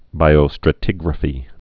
(bīō-strə-tĭgrə-fē)